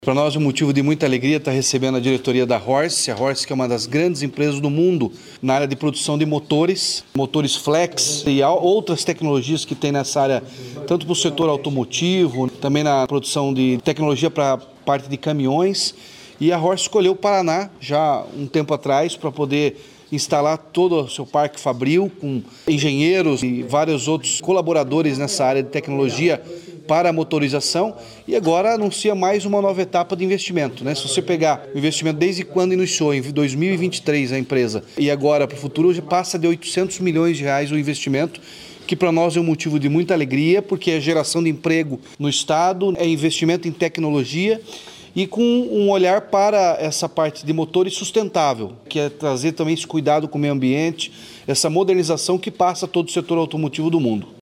Sonora do governador Ratinho Junior sobre o novo investimento da Horse Brasil no Paraná